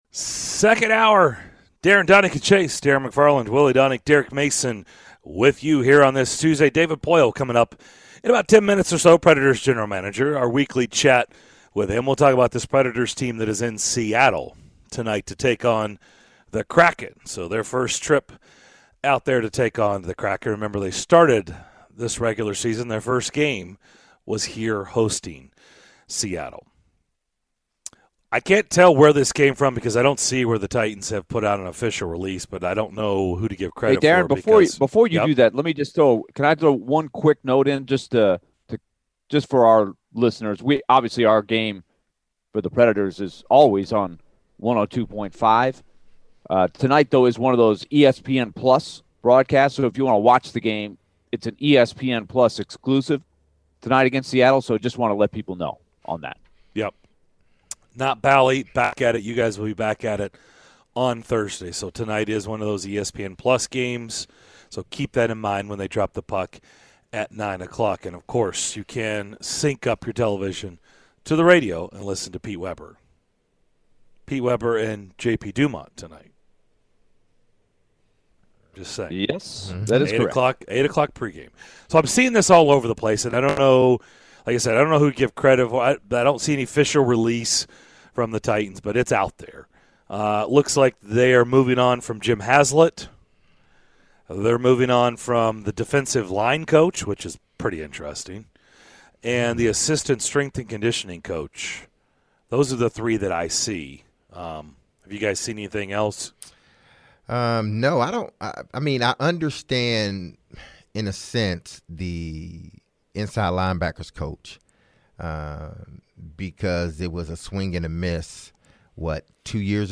In the second hour of Tuesday's show: the guys chat with Preds GM David Poile, break down the latest from the Preds heading into their game against the Kraken and more!